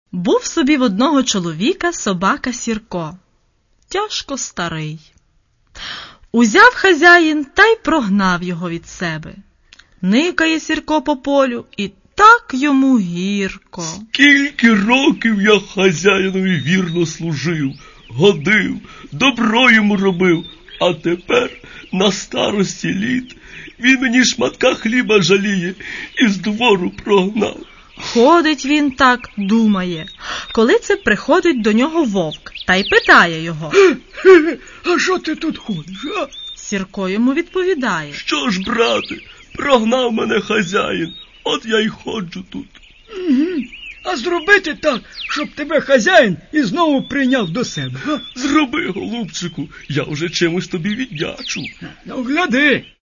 И не просто так начитанных – а рассказанных ярко, образно, эмоционально (и правда, хорошо здесь поработали актеры юмористического театра "КУМ"). Еще и с музыкальным сопровождением – как же без музыки?